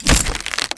mod_mine / sound / break / pd_wood1.wav
pd_wood1.wav